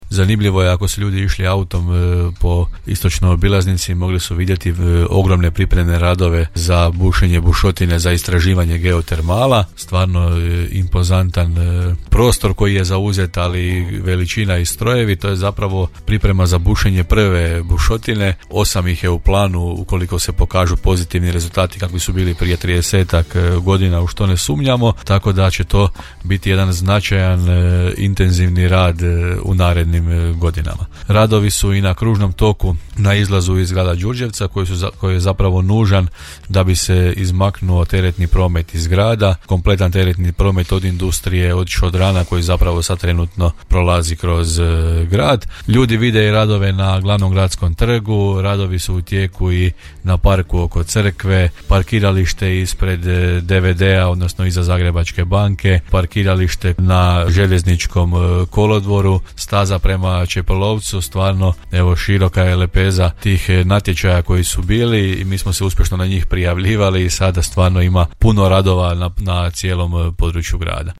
Kazao je to u emisiji Gradske teme u programu Podravskog radija gradonačelnik Grada Đurđevca Hrvoje Janči te se osvrnuo na radove na prometnoj infrastrukturi na području grada;